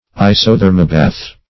Search Result for " isothermobath" : The Collaborative International Dictionary of English v.0.48: Isothermobath \I`so*ther"mo*bath\, n. [Iso- + Gr.